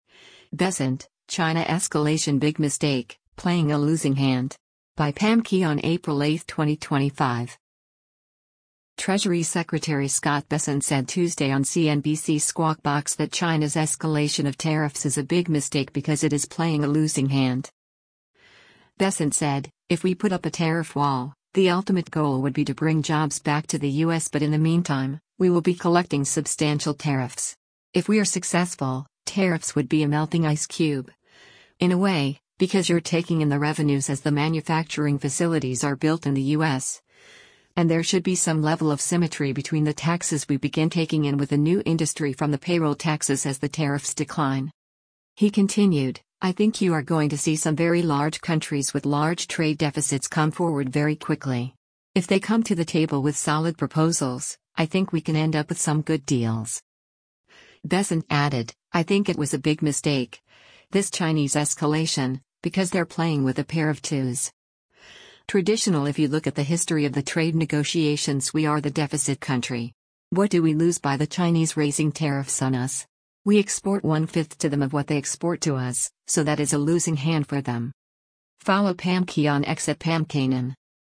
Treasury Secretary Scott Bessent said Tuesday on CNBC’s “Squawk Box” that China’s escalation of tariffs is a “big mistake” because it is playing a “losing hand.”